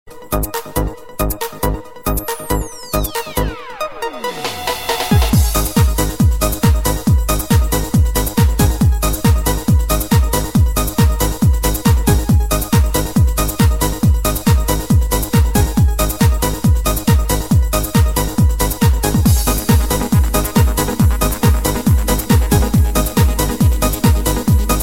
Ringtones Category: Instrumental